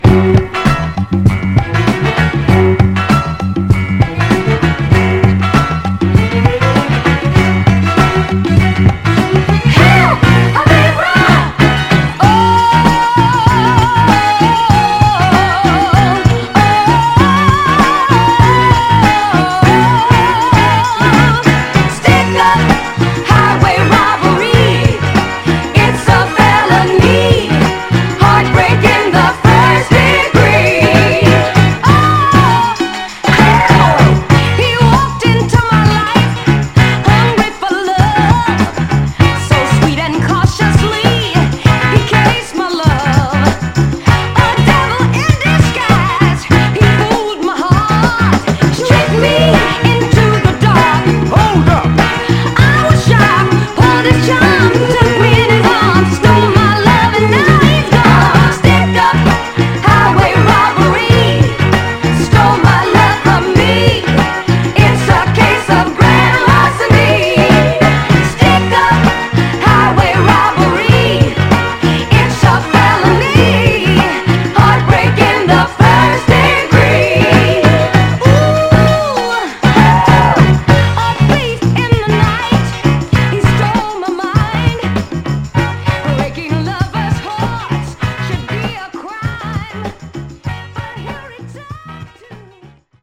直系の瑞々しいヴォーカルが最高なミッドテンポ・ソウルです。
高揚感もあり、序盤のコーラスでいきなり盛り上がります！
※試聴音源は実際にお送りする商品から録音したものです※